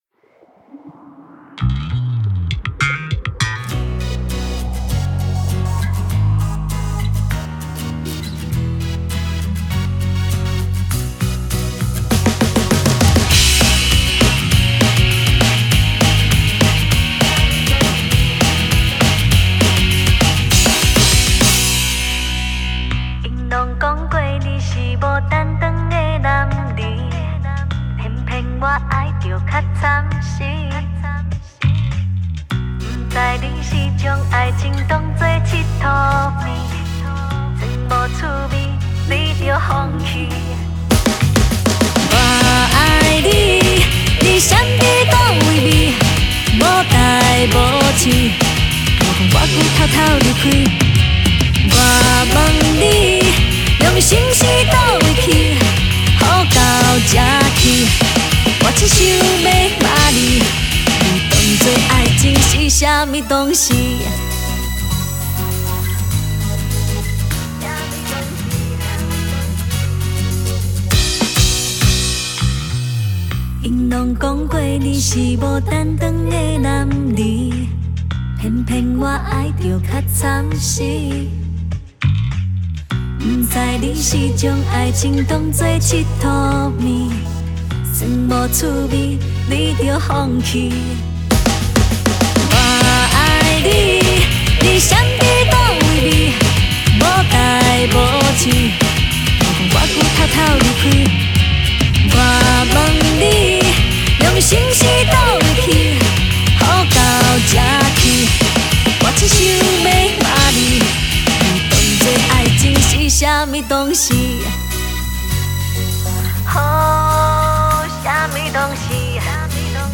以轻快硬式摇滚曲风，